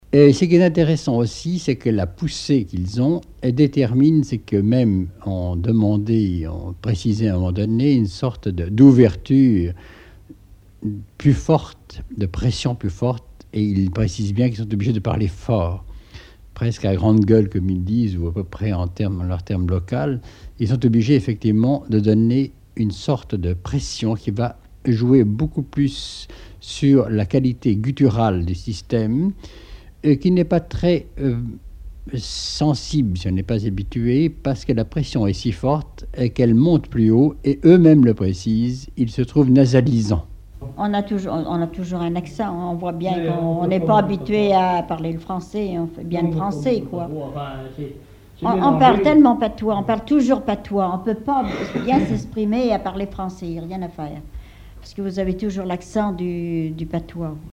La qualité guturale du maraîchin
Catégorie Témoignage